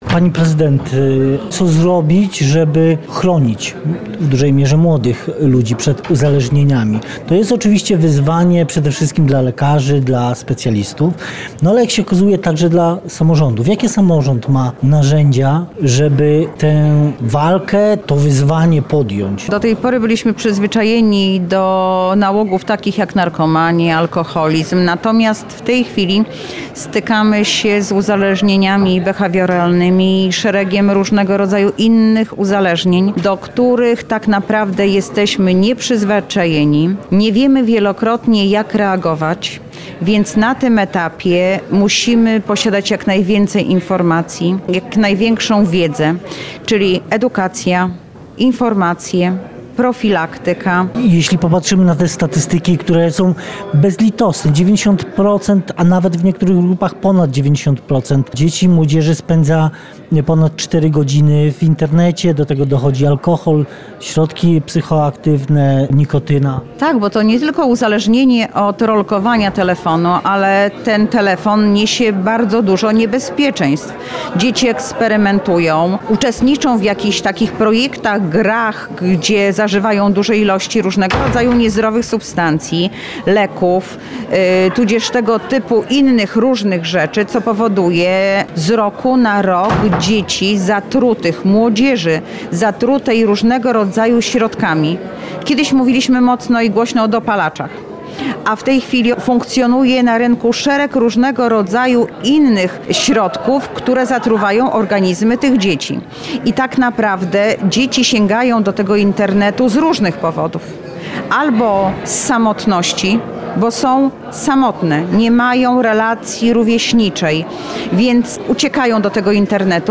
z Anną Augustyniak, zastępcą prezydenta Lublina do spraw społecznych